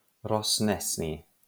.wav Audio pronunciation file from the Lingua Libre project.